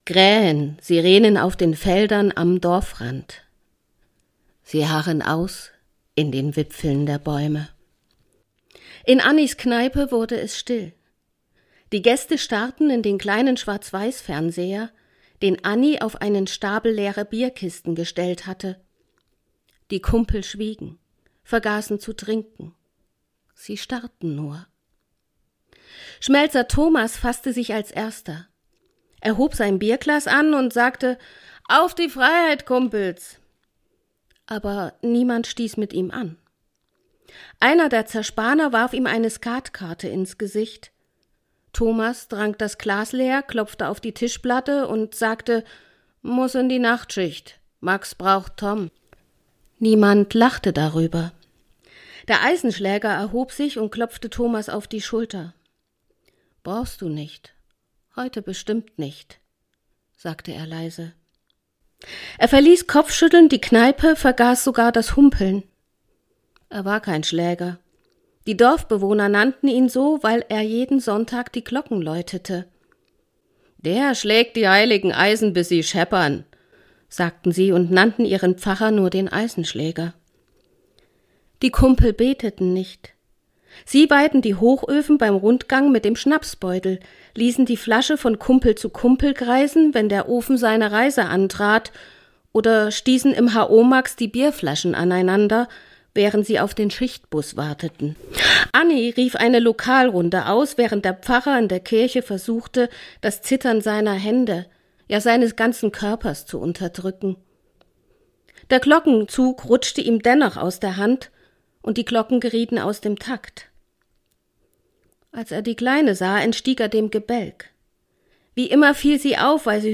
liest aus ihrem unveröffentlichten Roman »Was das Leben hergibt«.